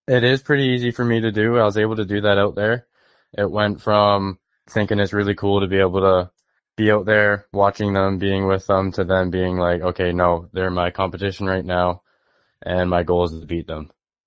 In an interview with Quinte News, he says it is a career highlight.